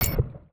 UIClick_Menu Water Splash Metal Hit 02.wav